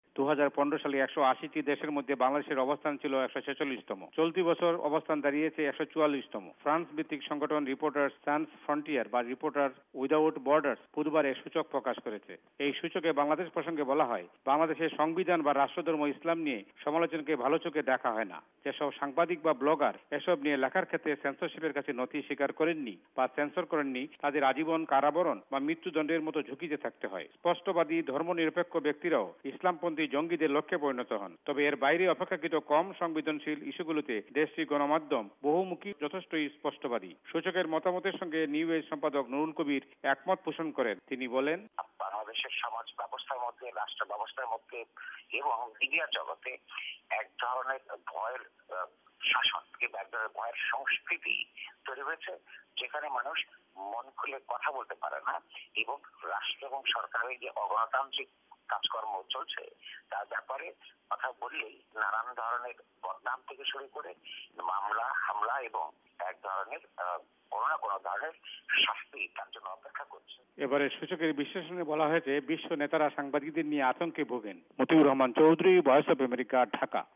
by ভয়েস অফ আমেরিকা